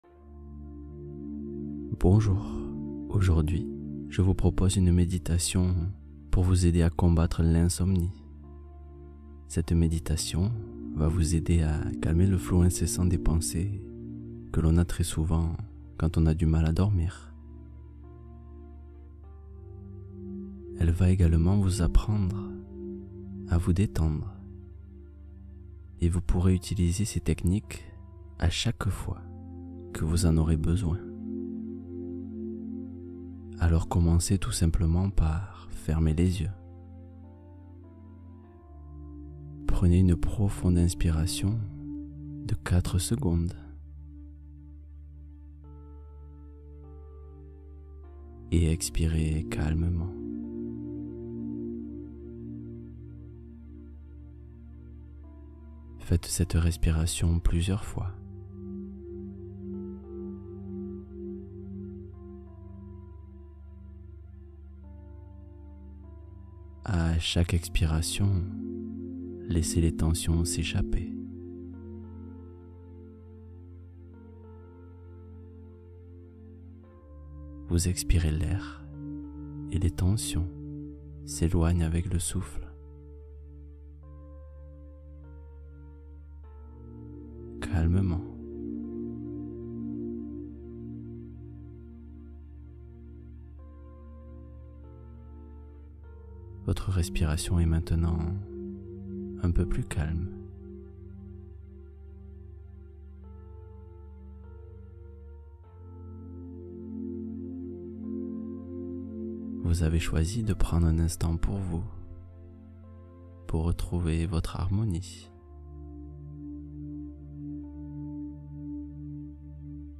Sommeil réparateur : détente guidée du soir